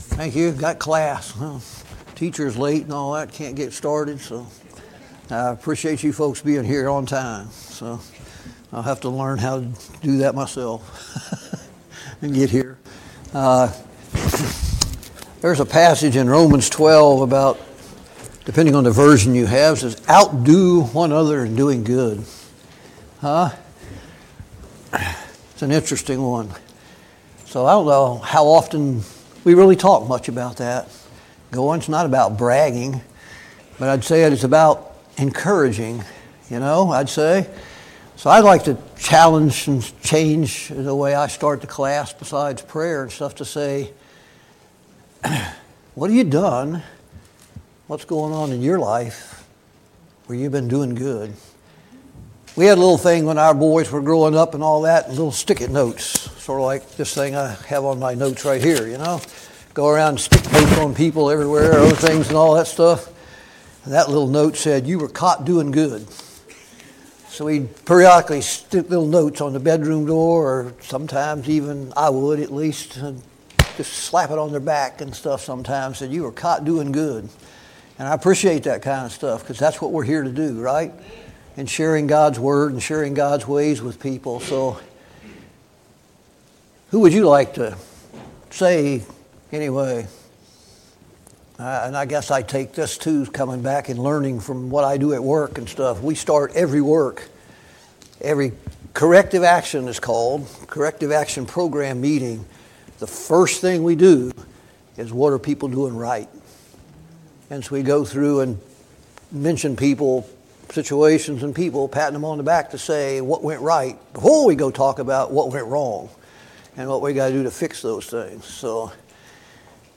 Sunday Morning Bible Class « Study of Paul’s Minor Epistles